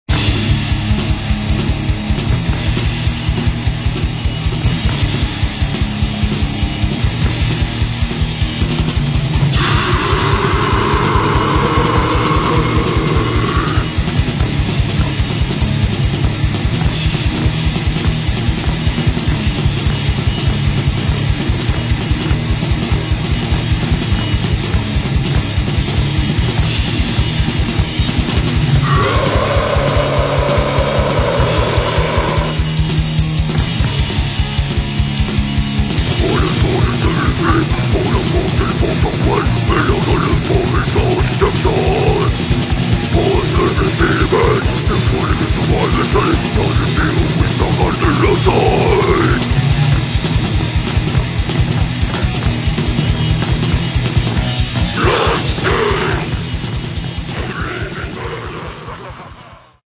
Брутальный Death Metal.
Некоторое влияние Crust и Grind.